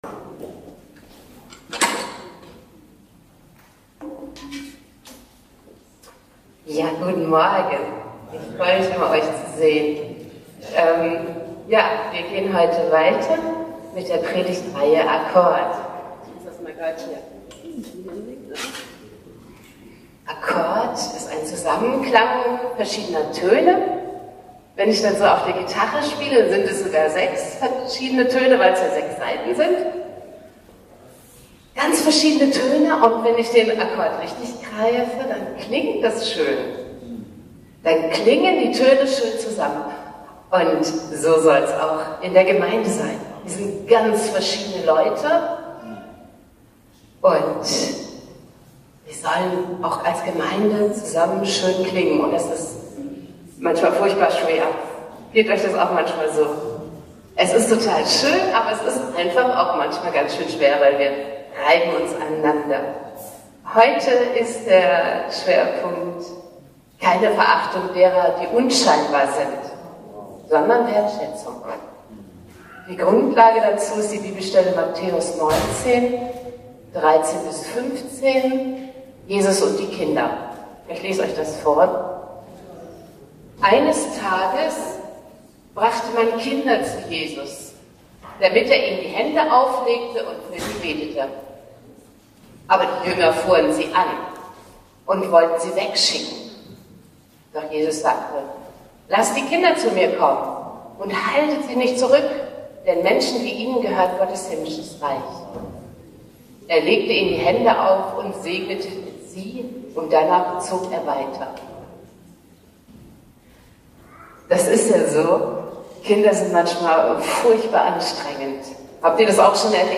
Juli 2024 29 Minuten Predigtreihe Akkord 4.